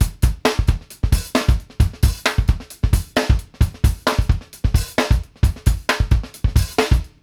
FOOT FUNK -R.wav